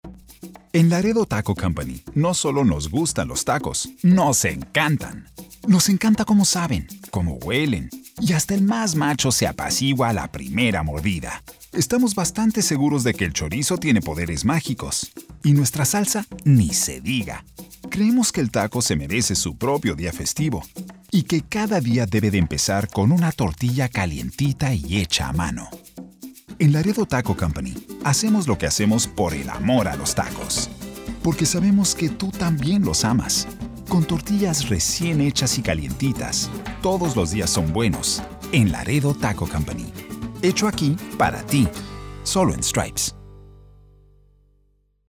Comercial Laredo Taco en español